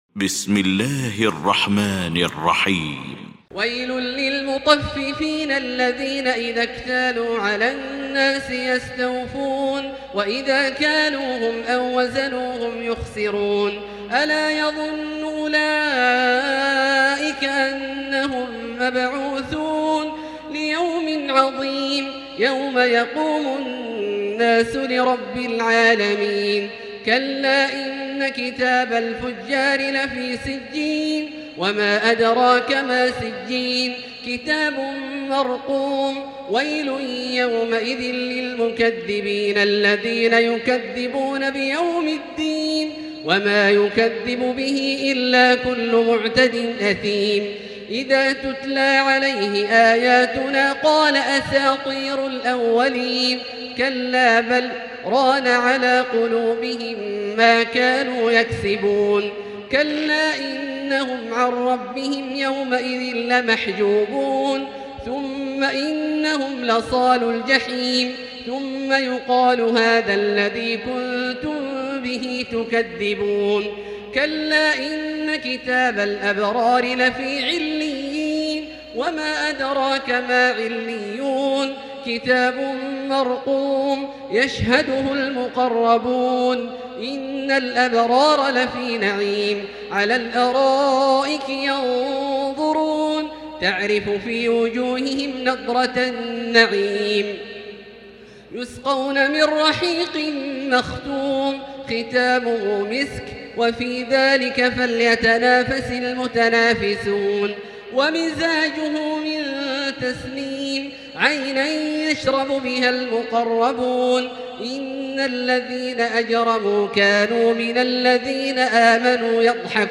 المكان: المسجد الحرام الشيخ: فضيلة الشيخ عبدالله الجهني فضيلة الشيخ عبدالله الجهني المطففين